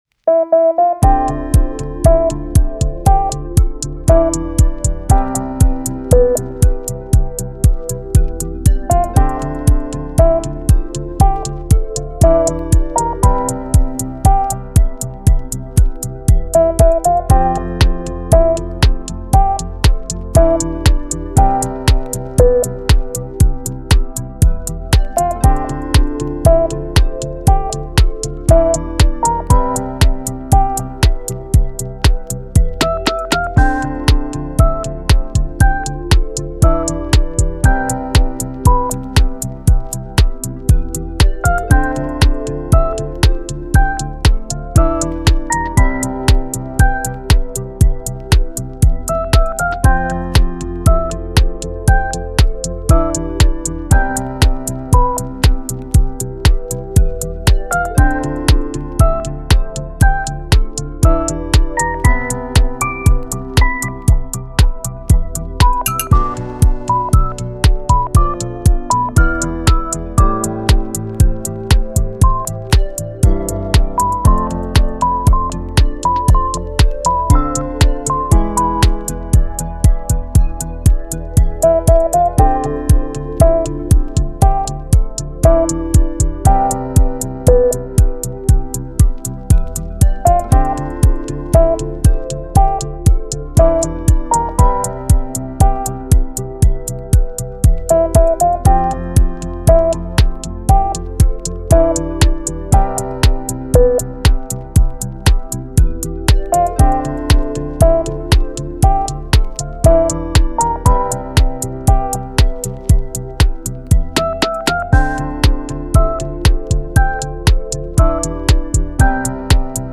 メロウ・切ない